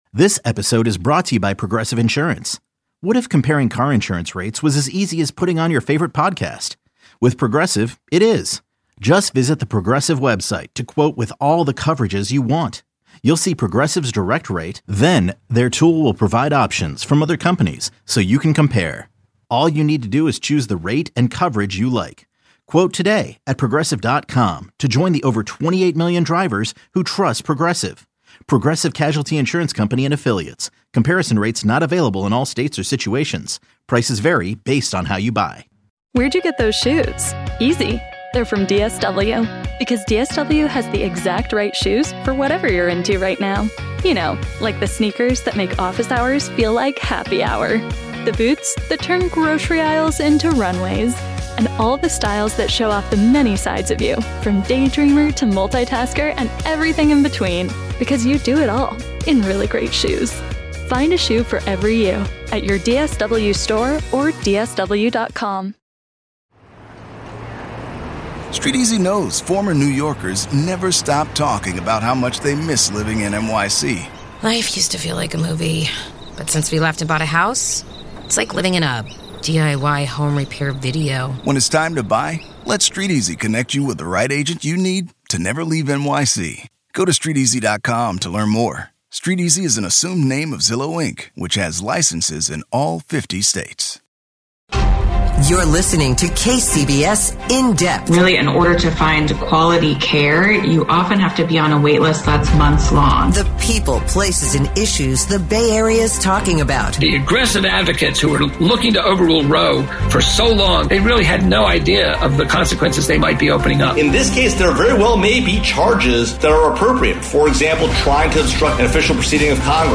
1 INTERVIEW: What are the ramifications of the Oval Office row last week? 20:30